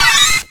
Cri de Pichu dans Pokémon X et Y.